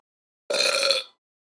burp-4alvttqa.mp3